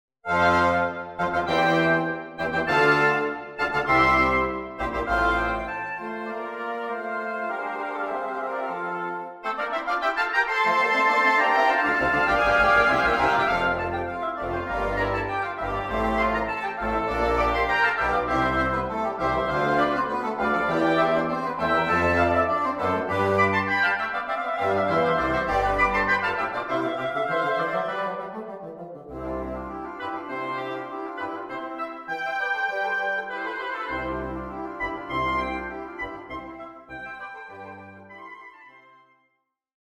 Two Oboes Two Cors Anglais Two Bassoons Contrabassoon